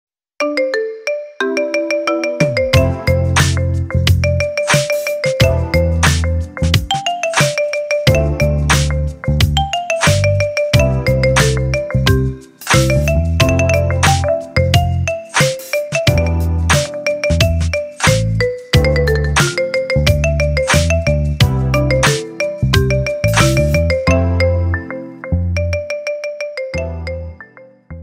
Kategori Marimba